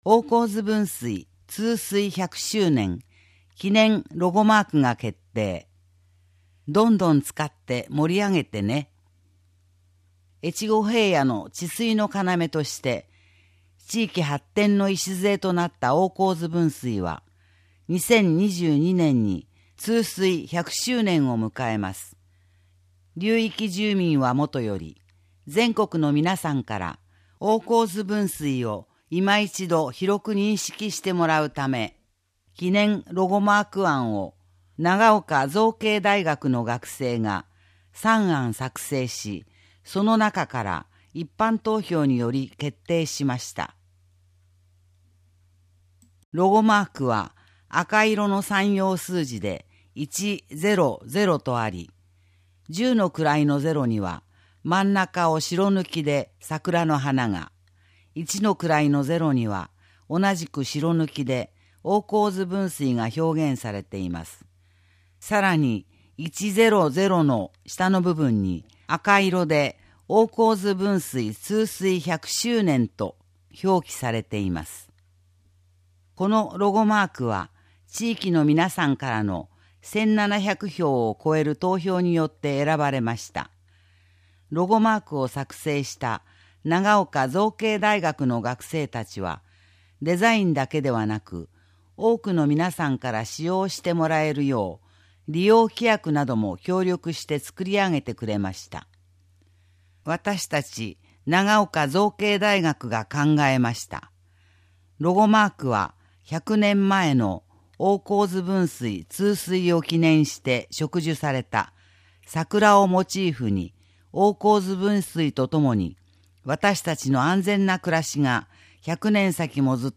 声の広報は、広報つばめを音読・録音したもので、デイジー版とMP3版があります。